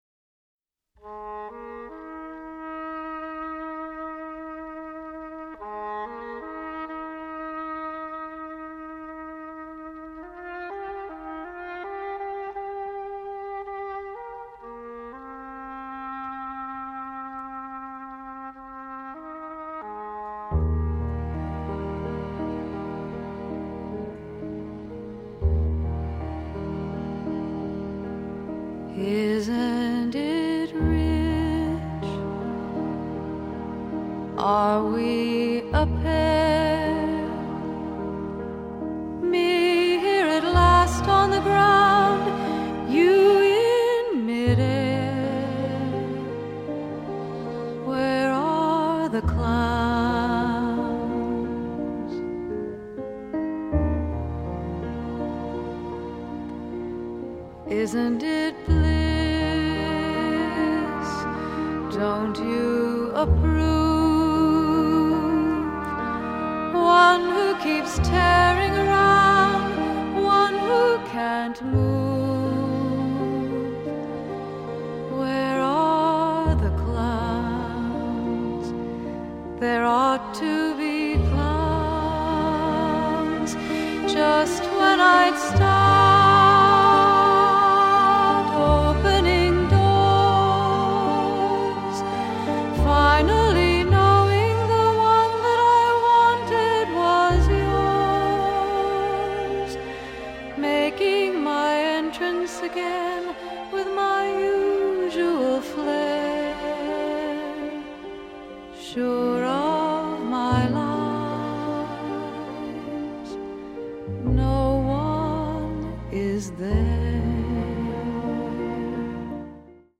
★ 以Hi-Res格式製作母帶重現巨星的委婉動人歌聲！